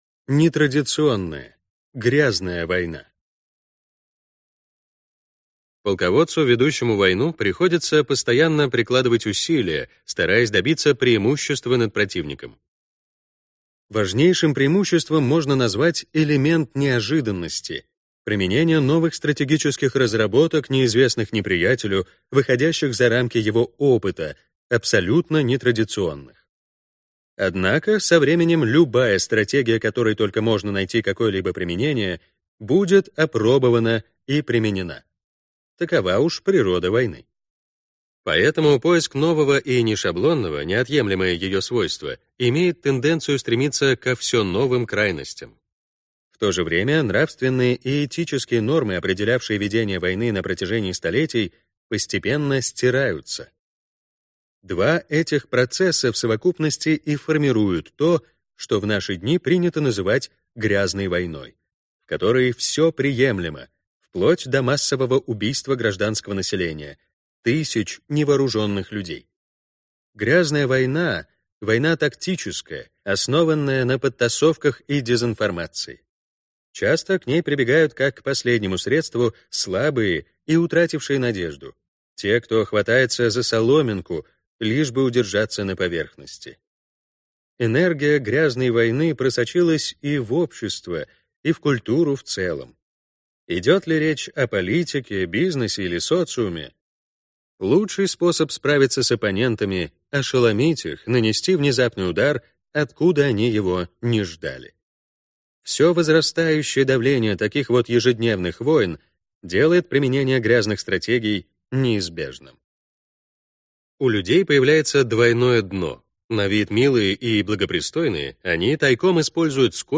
Аудиокнига 33 стратегии войны. Часть 5 | Библиотека аудиокниг